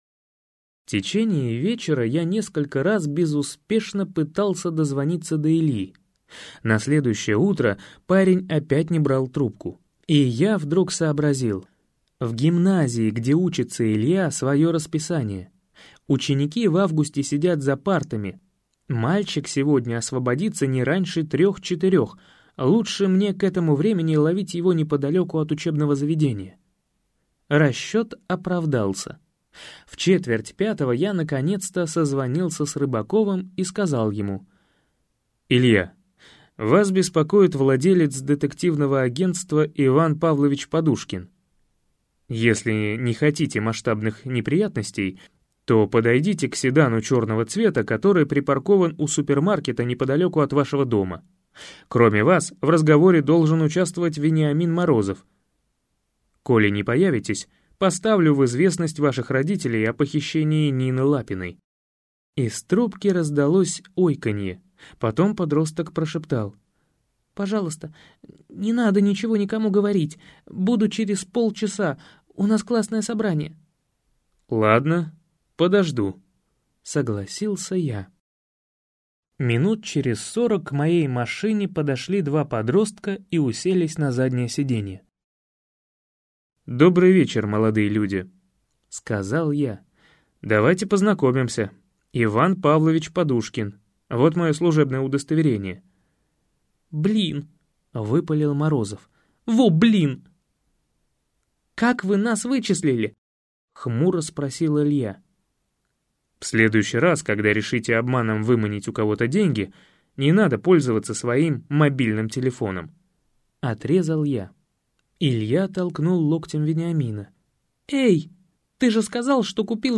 Аудиокнига Кто в чемодане живет?